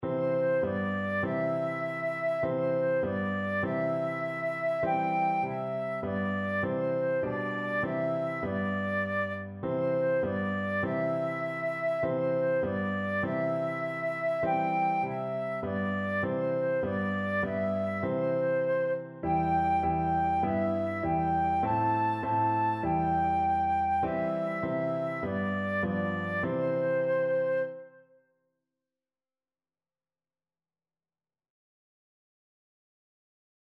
Flute
C major (Sounding Pitch) (View more C major Music for Flute )
4/4 (View more 4/4 Music)
Simply
Traditional (View more Traditional Flute Music)